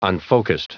Prononciation du mot unfocussed en anglais (fichier audio)
Prononciation du mot : unfocussed